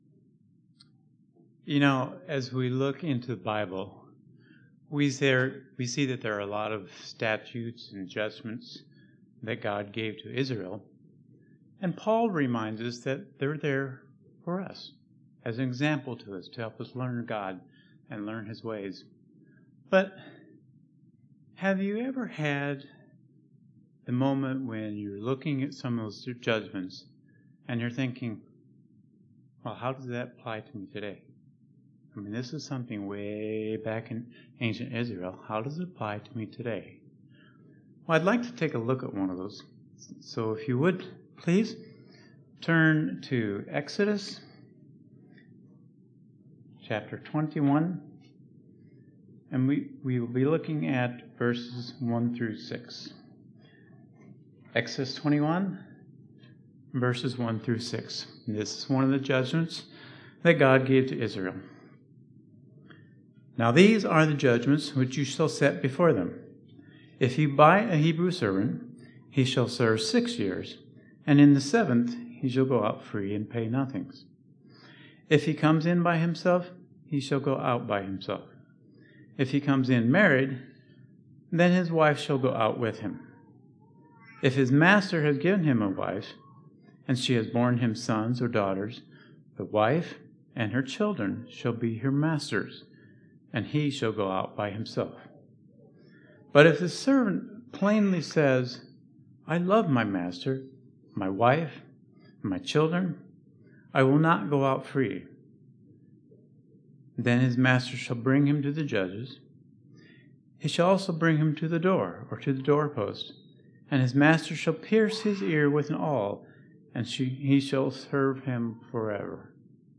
Exodus 21:1-6 describes a judgment from God for what should happen when an Israelite man would have to sell himself to a temporary master. This sermon looks at some key points of that judgment and how it is still relevant to us today.
Given in Northwest Indiana